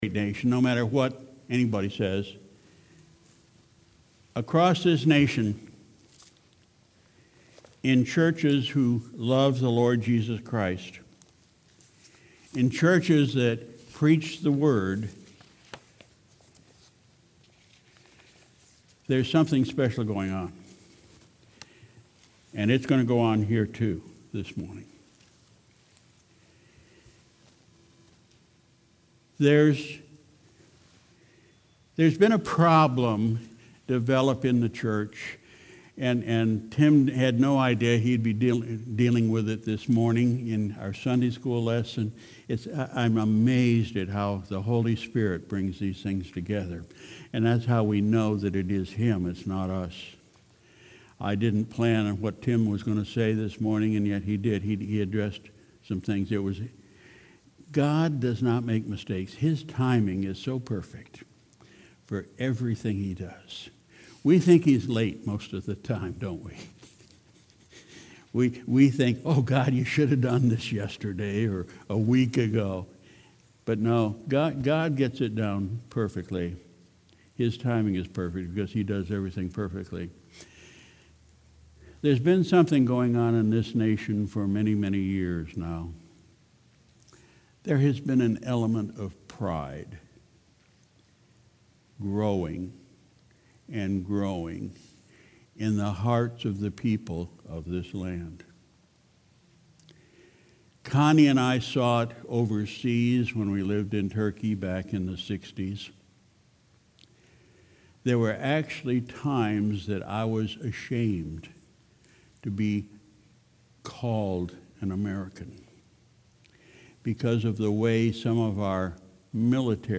Sunday, June 30, 2019 – Morning Service